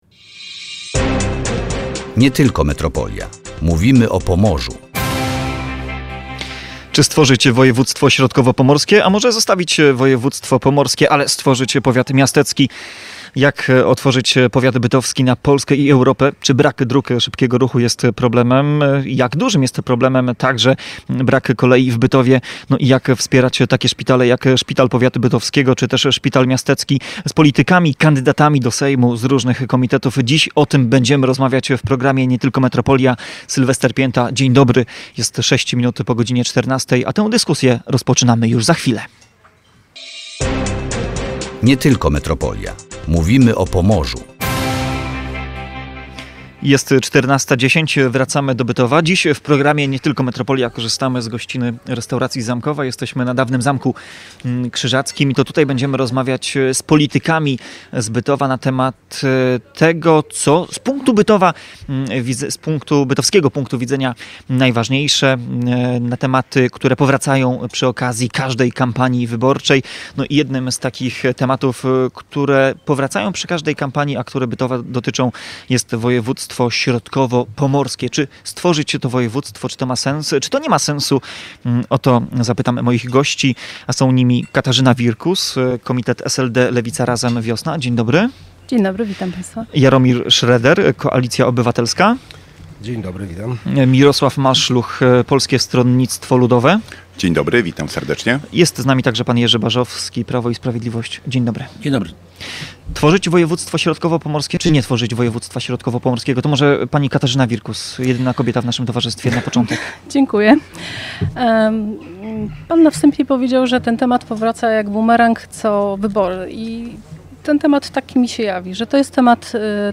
Między innymi o tym rozmawialiśmy z politykami z powiatu bytowskiego w audycji Nie tylko Metropolia.